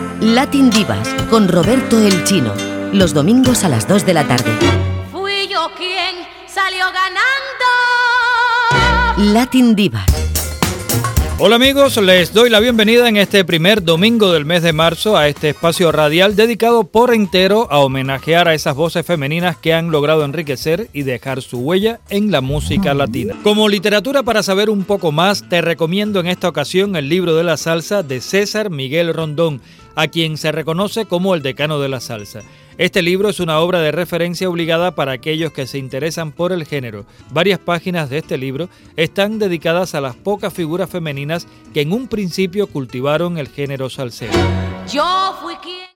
Careta, data, presentació i recomanació d'un llibre
Musical